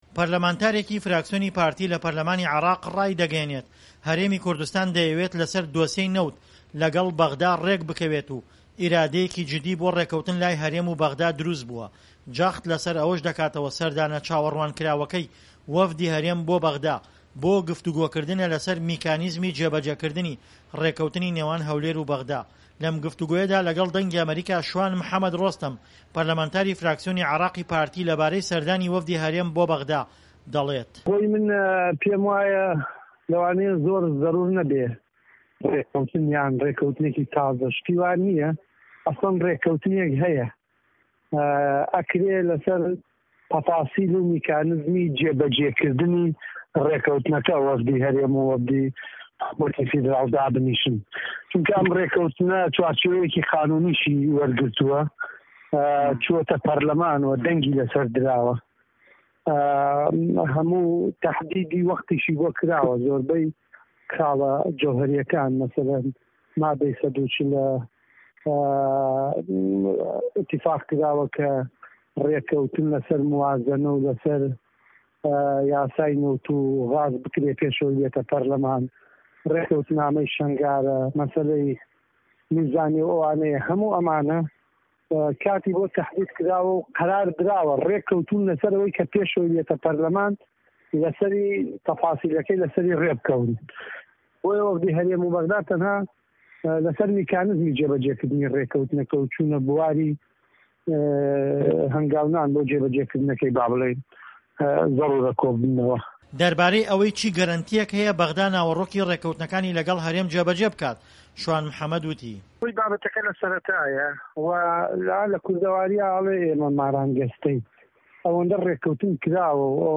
لەم گفتووگۆیەدا لەگەڵ دەنگی ئەمەریکا، شوان محەمەد رۆستەم پەرلەمانتاری فراکسیۆنی عێراقی پارتی، لەبارەی سەردانی وەفدی هەرێم بۆ بەغدا، دەڵێت"ڕێککەوتنی تازە لە نێوان هەولێر و بەغدا پێویست نییە، چونکە پێشتر ڕێککەوتن کراوە و دەکرێ لەسەر وردەکاری و میکانیزمی جێبەجێکردنی ڕێککەوتنەکە، وەفدی هەردوولا گفتووگۆ بکەن، بەتایبەت ڕێککەوتنەکە چوارچێوەی یاسایی وەرگرتووە و لەپەرلەمان دەنگی لەسەر دراوە."